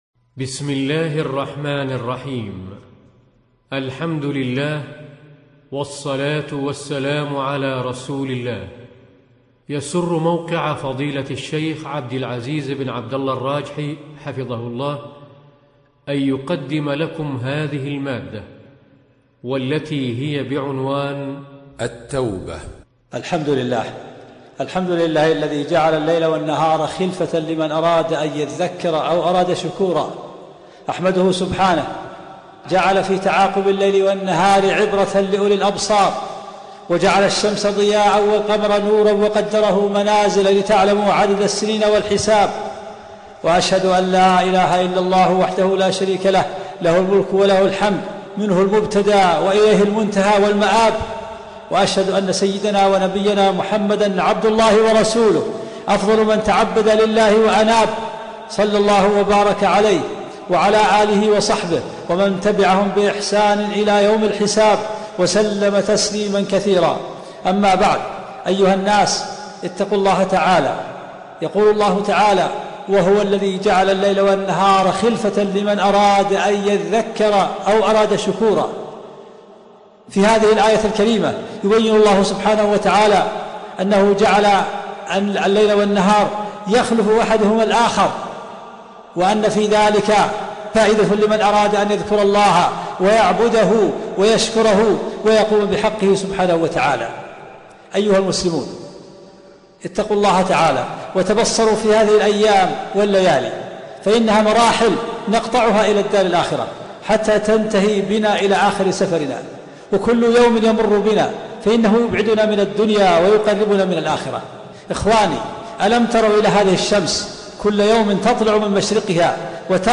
خطبه لشيخ عبدالعزيز الراجحي بعنوان التوبه